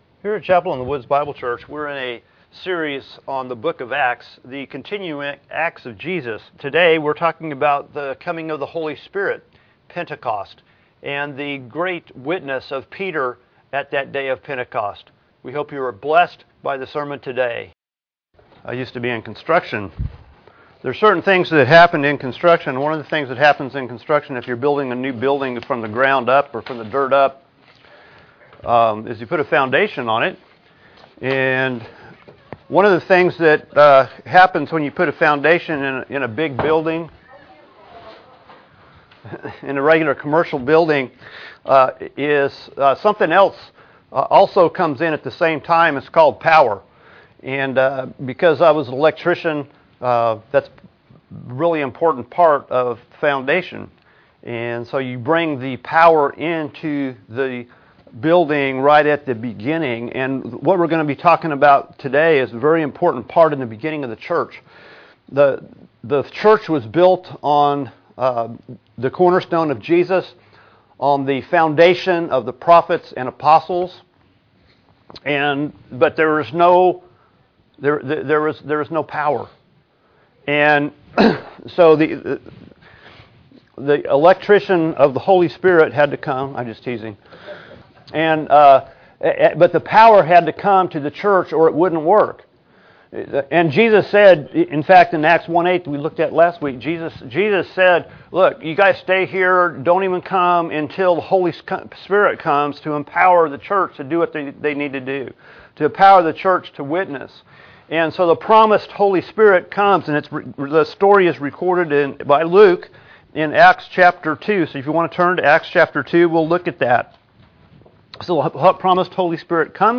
Jan 21, 2018 Acts 2 The Spirit – Power for Witness MP3 SUBSCRIBE on iTunes(Podcast) Notes Discussion Sermons in this Series It is time for the Disciples to start their mission of witness about Jesus.